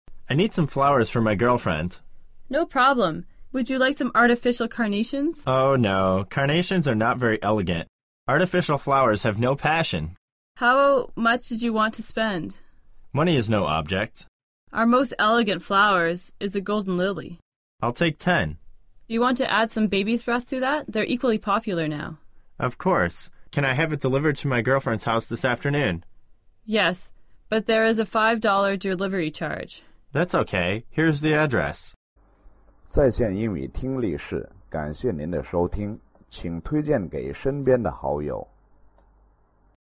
英语口语900句 11.05.对话.2.情人节送花 听力文件下载—在线英语听力室